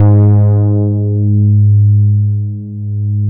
MODULAR G#3P.wav